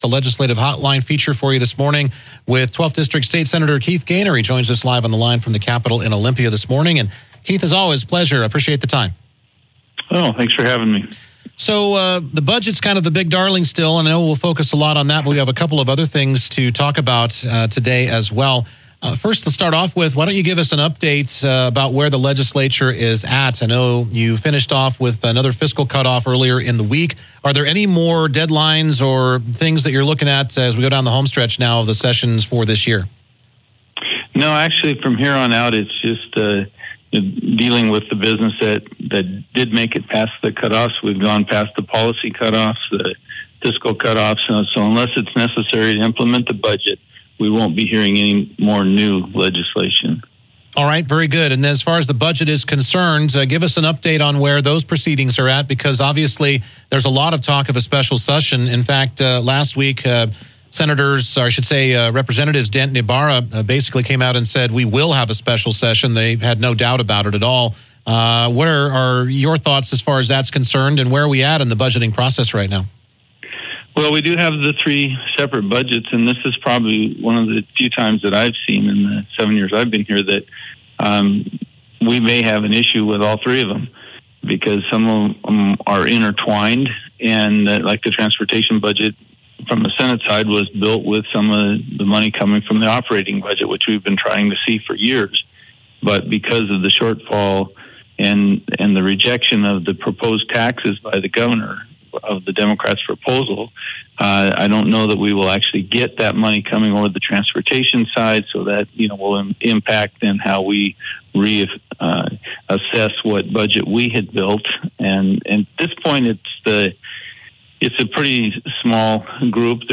Sen. Keith Goehner’s KPQ Radio interview tackled budget challenges, tax proposals, transportation funding, and rent control legislation.